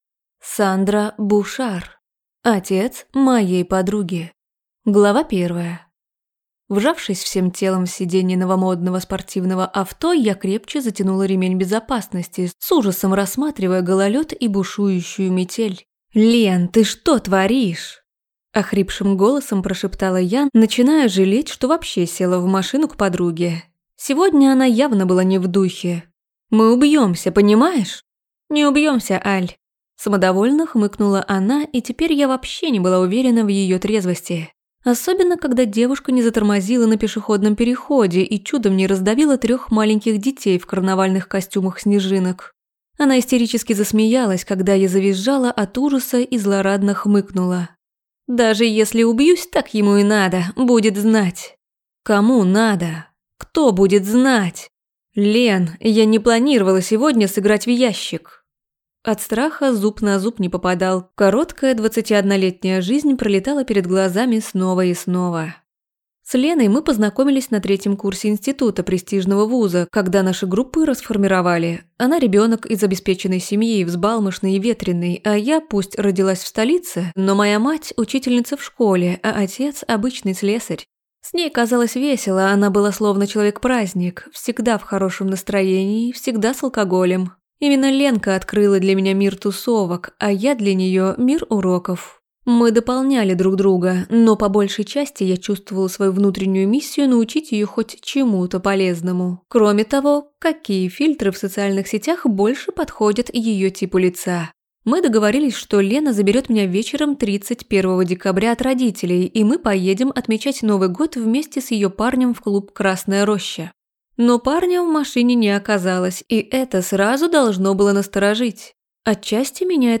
Аудиокнига Отец моей подруги | Библиотека аудиокниг